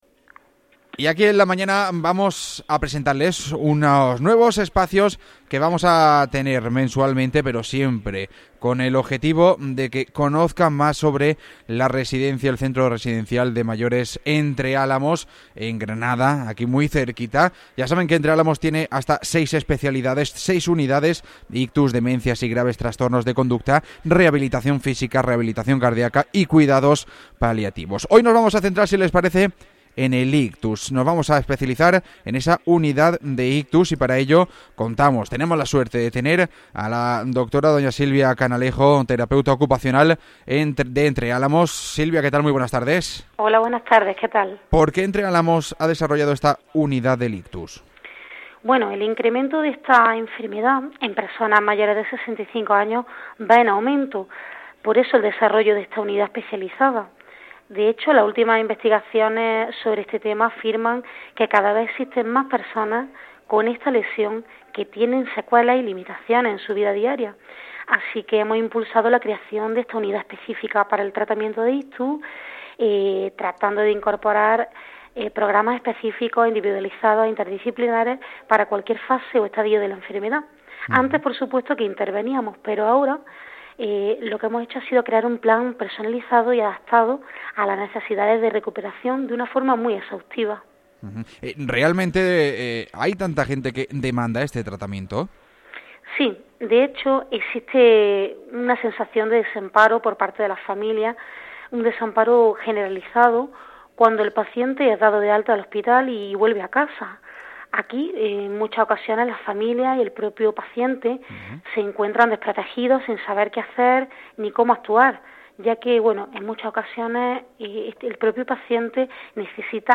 Micro espacio informativo